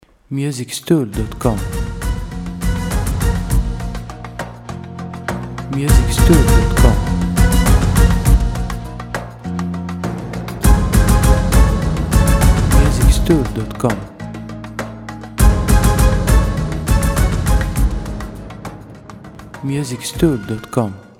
• Type : Instrumental / Audio Track
• Bpm : Allegretto
• Genre : Oriental